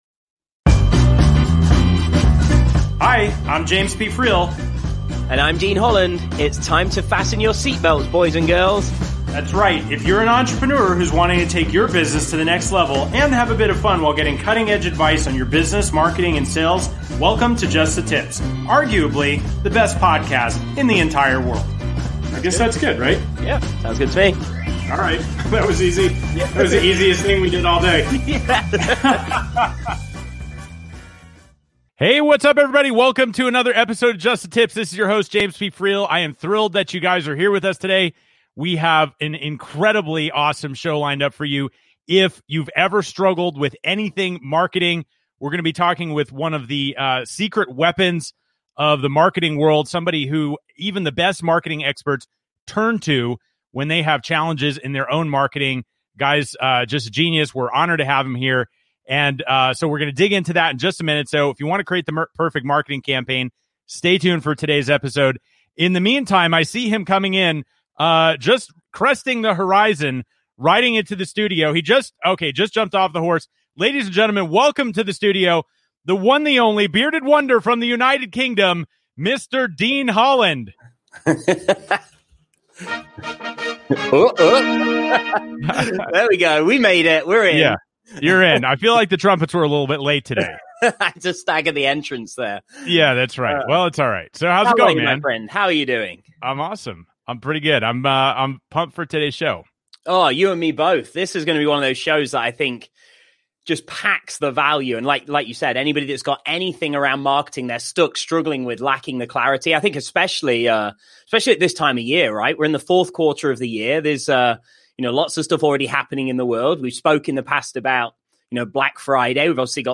Talk Show
Just The Tips is a business talk show for entrepreneurs and business owners who are tired of listening to the same old stodgy content. Interviewing (and sometimes roasting) top entrepreneurs from around the world, each episode is fun, witty and informative.